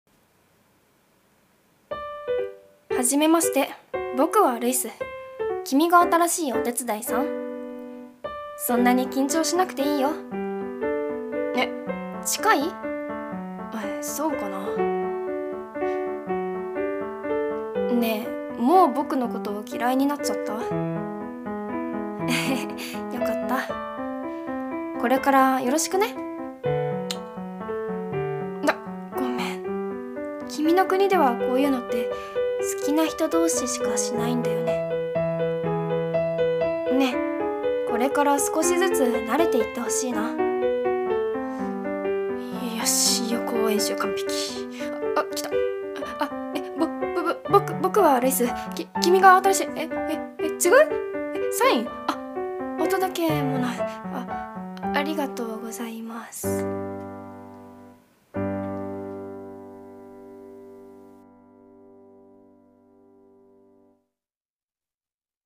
一人声劇】étude